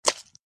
mud.mp3